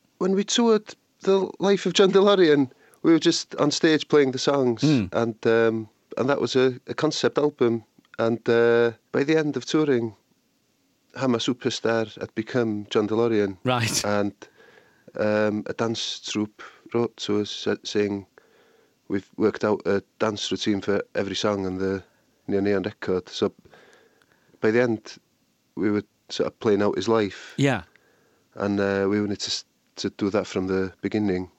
Gruff Rhys talks to Stuart Maconie about how Neon Neon's last album evolved whilst on tour, featuring Har Mar Superstar and a dance troupe!